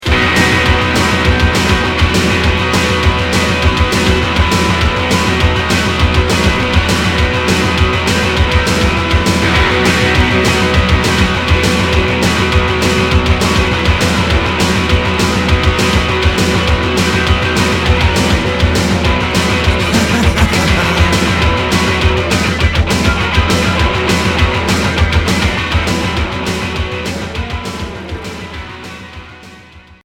Rock indus